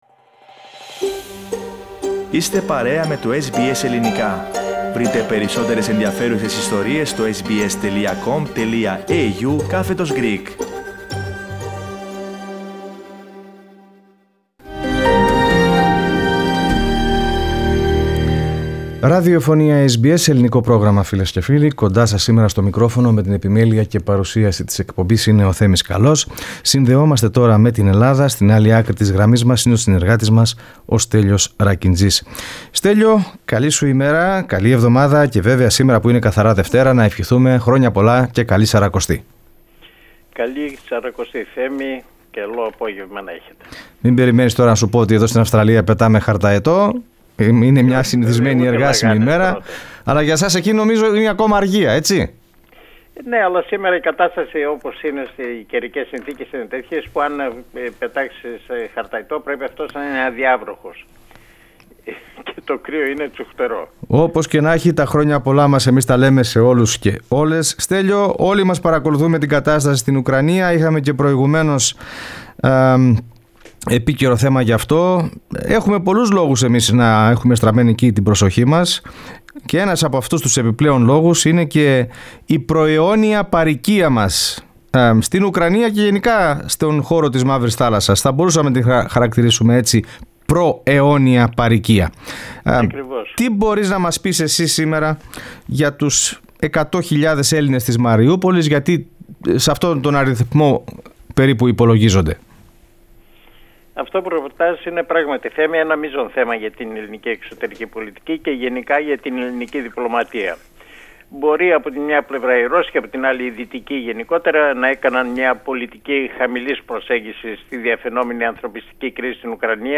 Στην σημερινή ανταπόκριση από Αθήνα, αυξάνεται η αγωνία για τους ομογενείς που κατοικούν στην Μαριούπολη, διχασμένη εμφανίζεται η ελληνική κοινή γνώμη για την παροχή βοήθειας προς τους Ουκρανούς ενώ επιβεβαιώθηκε και η επικείμενη συνάντηση του Κυριάκου Μητσοτάκη με τον Ταγίπ Ερντογάν